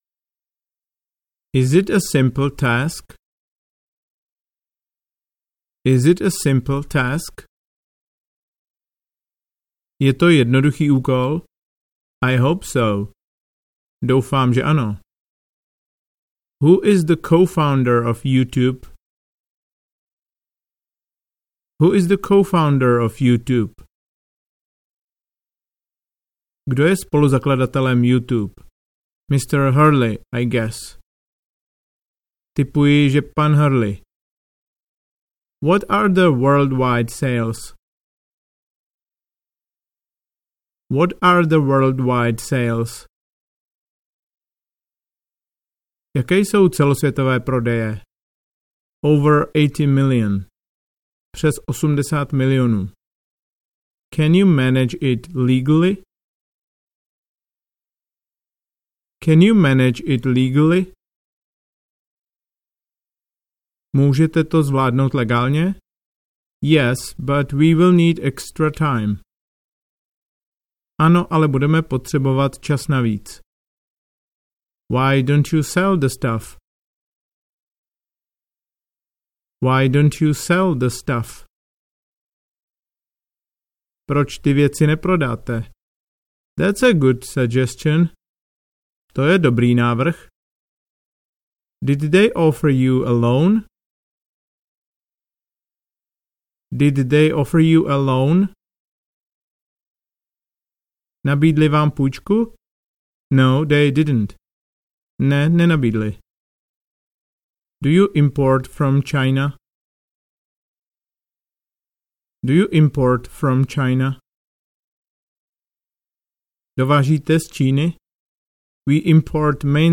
Audio knihaKonverzační business otázky a odpovědi
Ukázka z knihy